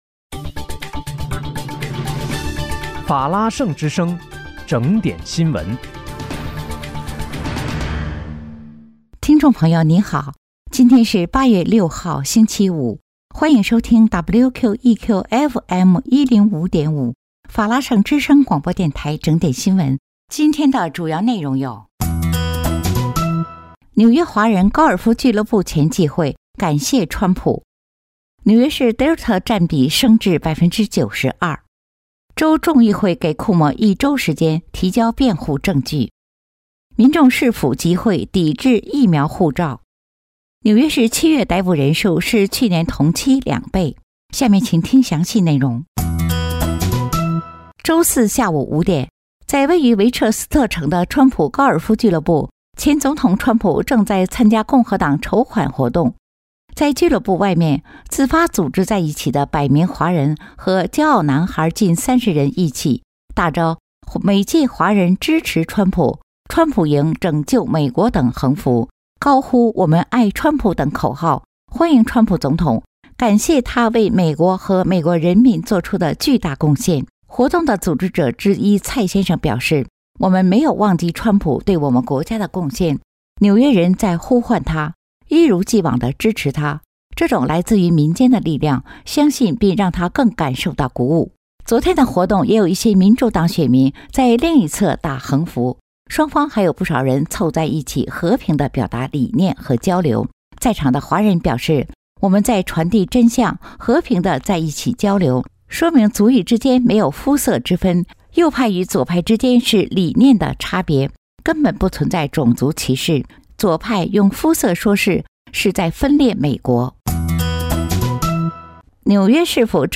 8 月6日（星期五）紐約整點新聞